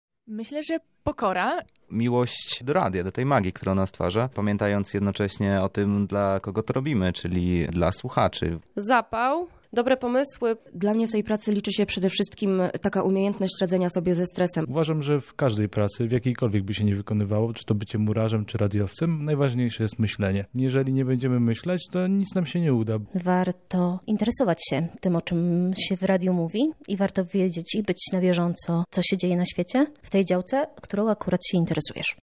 A co jest najważniejsze w tej pracy? O to zapytaliśmy dziennikarzy Radia Centrum.